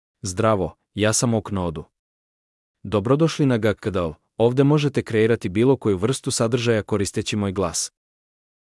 Nicholas — Male Serbian AI voice
Nicholas is a male AI voice for Serbian (Cyrillic, Serbia).
Voice sample
Listen to Nicholas's male Serbian voice.
Male
Nicholas delivers clear pronunciation with authentic Cyrillic, Serbia Serbian intonation, making your content sound professionally produced.